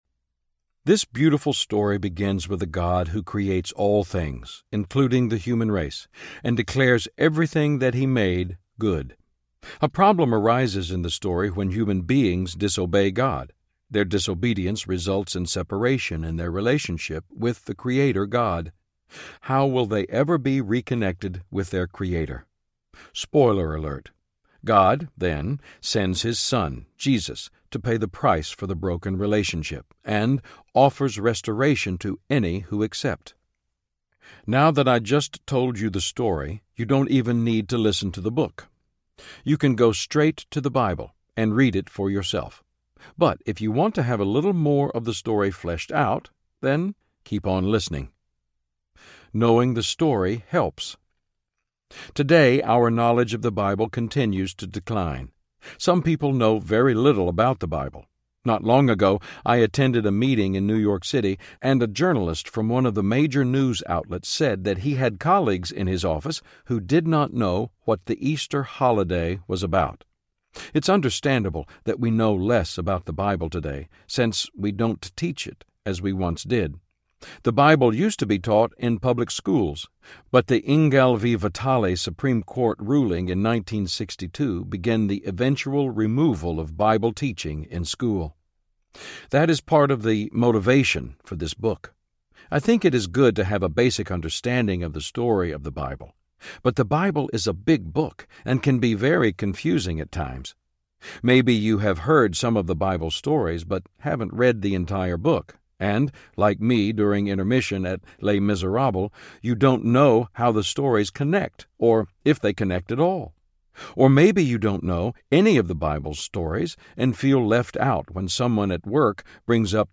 This Beautiful Book Audiobook
Narrator
5.3 Hrs. – Unabridged